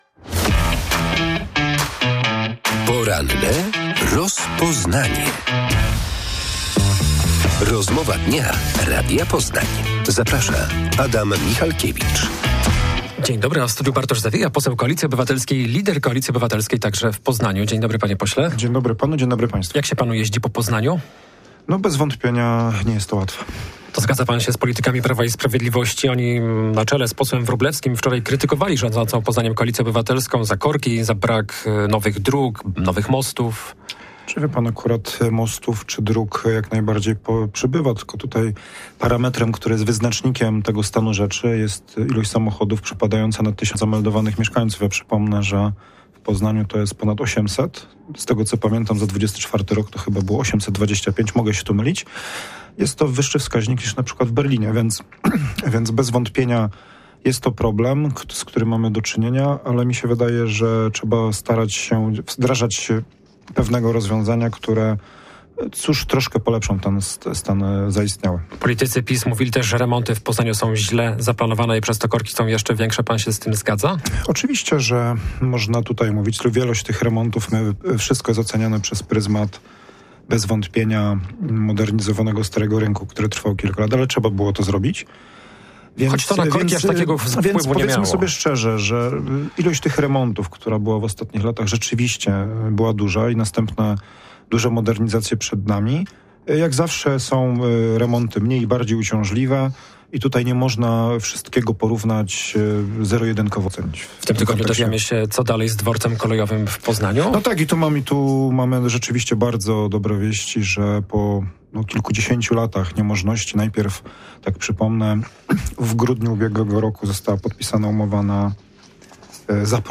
W porannej rozmowie Radia Poznań poseł Bartosz Zawieja z Koalicji Obywatelskiej przyznał, że według jego informacji stary i zamknięty od lat gmach dworca ma być wyburzony.